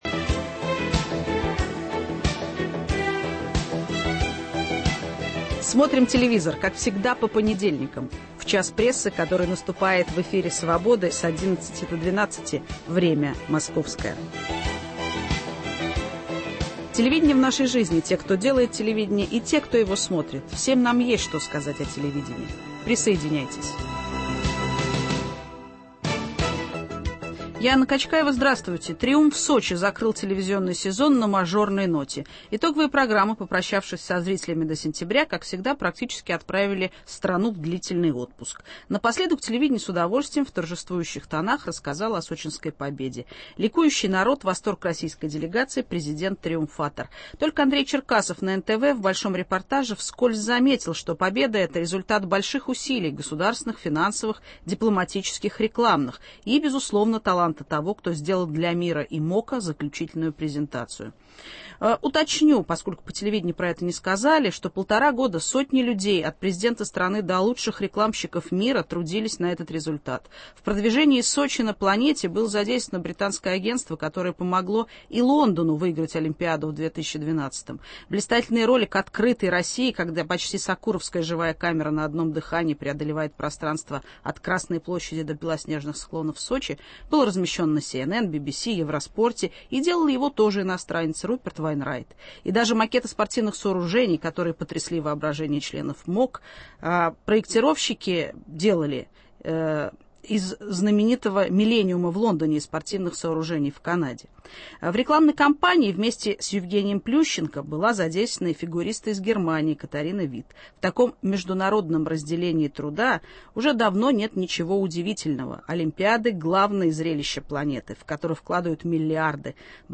Поговорив на минувшей неделе об итогах сезона с коллегами-журналистами, попробуем подвести под эти телевизионные итоги более философскую, научную базу. Гость студии - социолог культуры, главный редактор журнала «Искусство кино» Даниил Дондурей.